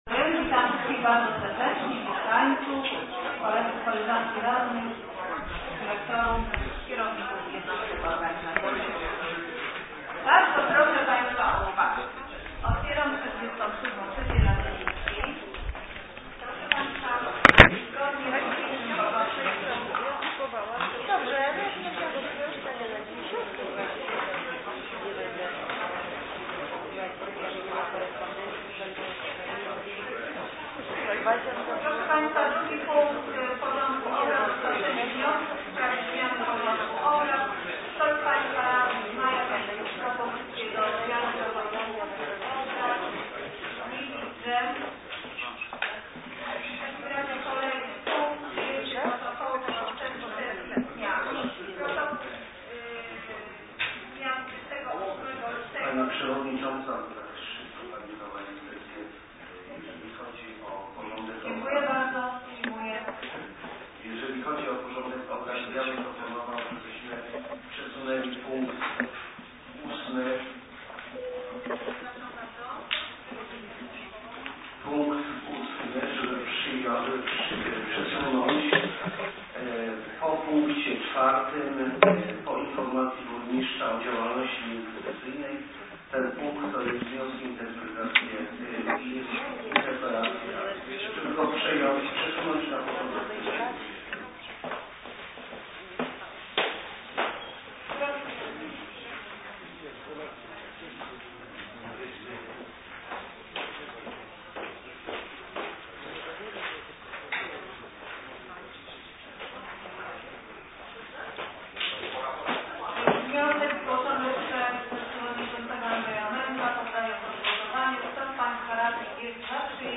Plik dźwiękowy z sesji XLVII w dniu 04.04.2013r.